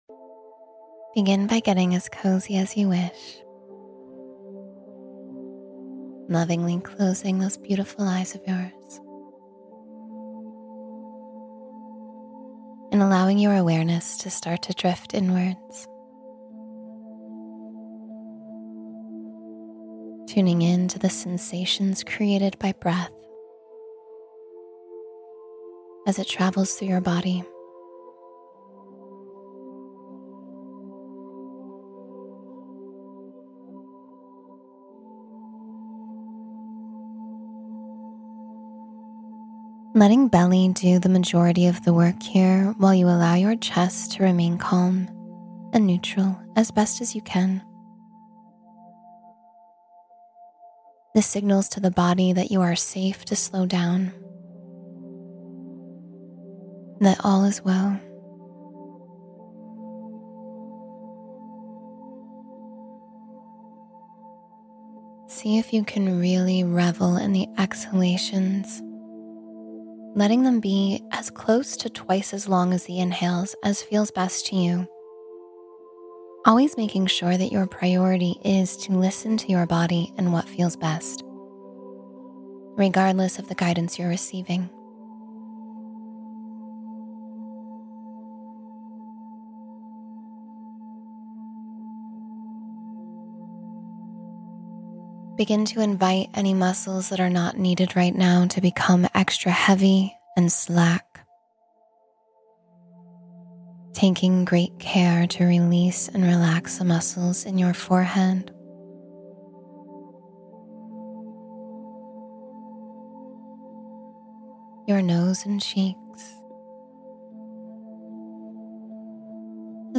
Let Go in 10 Minutes of Presence — Guided Meditation for Emotional Release